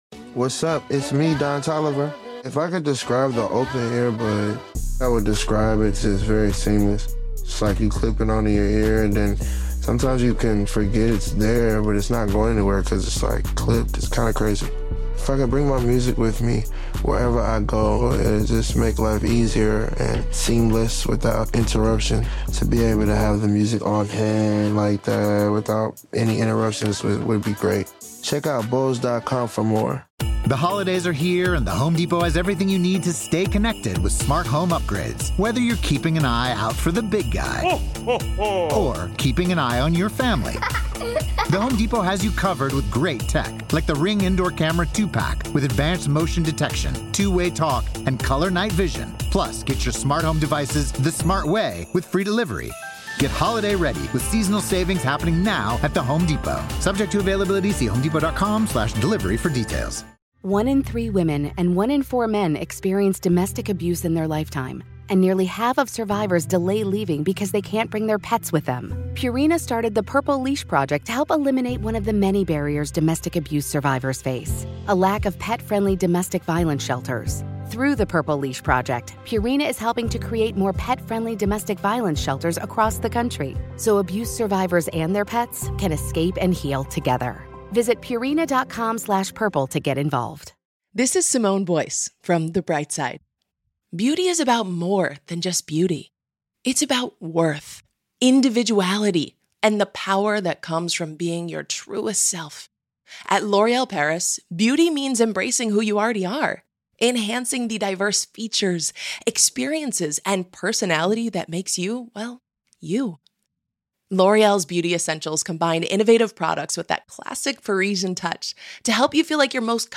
His death prompted an avalanche of tributes for a genuine, approachable, authentic person who nurtured the unsurpassed hockey talents of his son, Wayne Gretzky, on the family's famed backyard rink in Brantford, Ontario. The 60-year-old NHL legend-turned-American citizen spoke shortly after laying his father to rest.